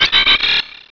pokeemerald / sound / direct_sound_samples / cries / masquerain.aif